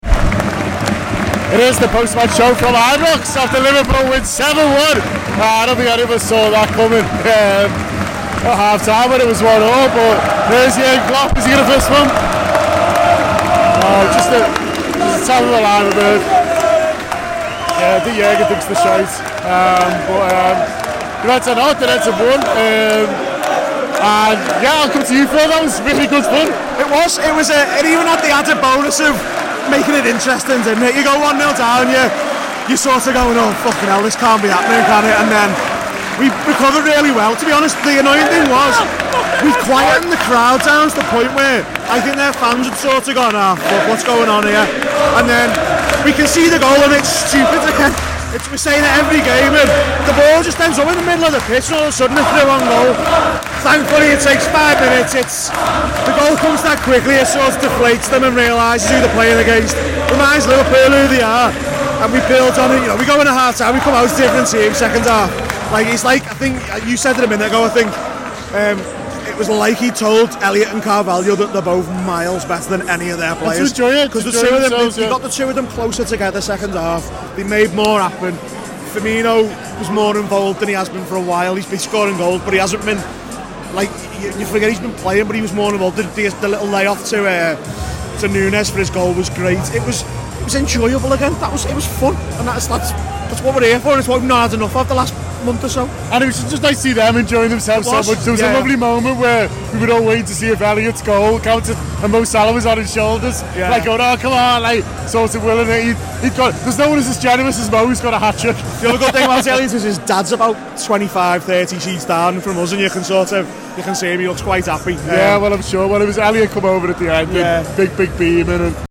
hosts from the away end in Glasgow…